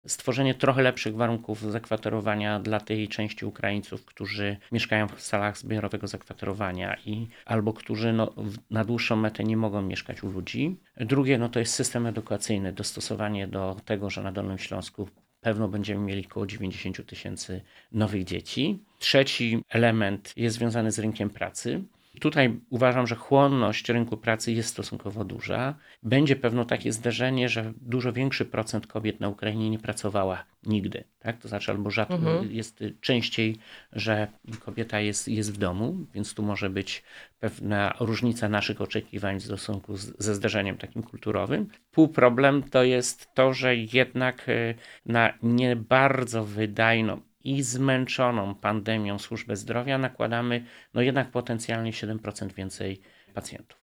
Wojewoda mówi o wyzwaniach stojących przed regionem w związku z napływem uchodźców.